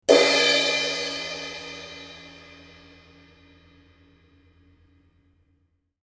華麗にして素早いクラッシュサウンド。明るくハイピッチが持ち味のモデル。